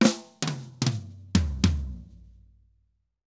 Drum_Break 110_2.wav